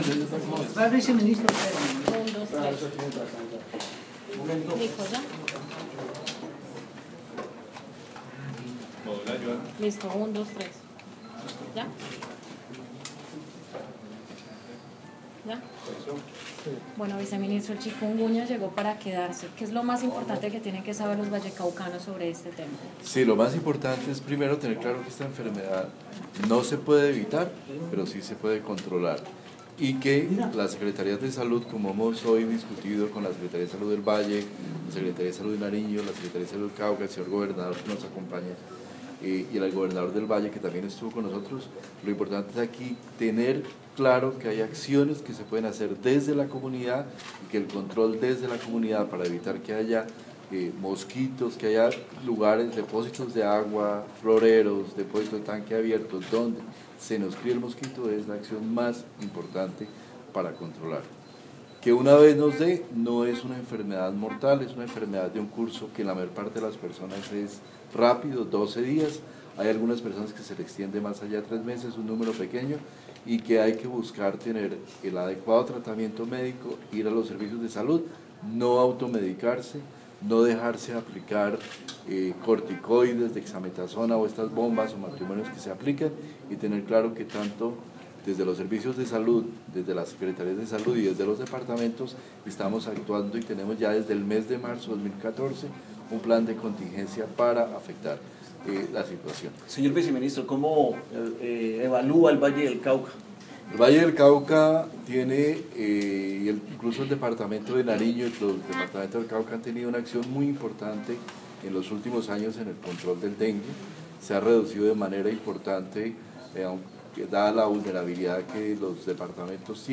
Audio, rueda de prensa en Cali, Viceministros Fernando Ruiz y tema Chikunguña